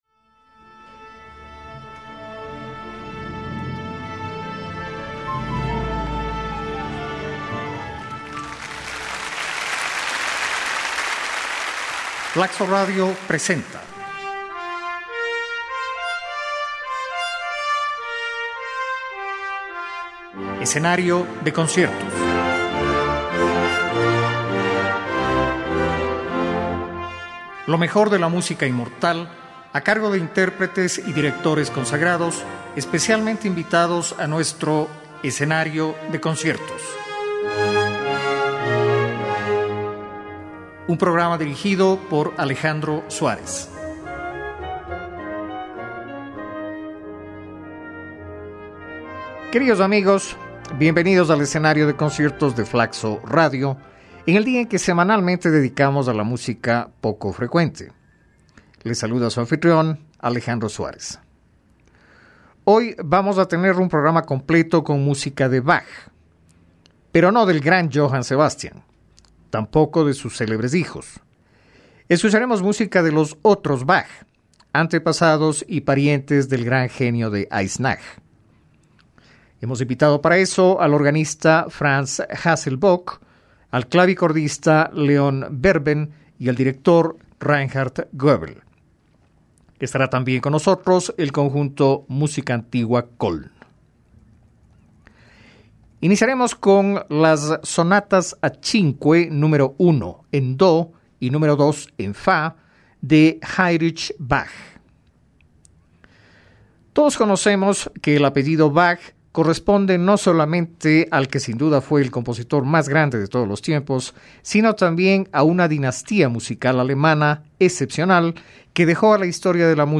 piano
CONCIERTO EN LA MENOR OP. 54 PARA PIANO Y ORQUESTA
SINFONIA N. 1 EN RE MAYOR OP. 25
Orquesta Sinfónica de Londres Director: Vladimir Ashkenazy